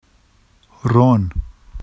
[ron] n oil